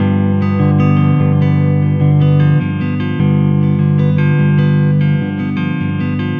Weathered Guitar 02.wav